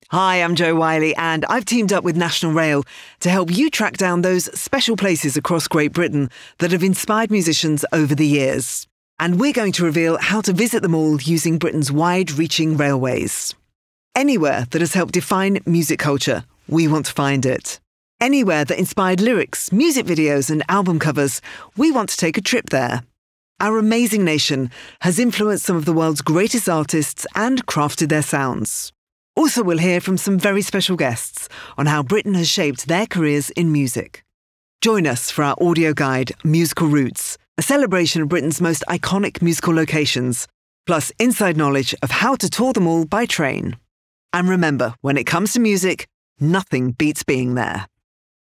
Jo Whiley’s introduction to Musical Routes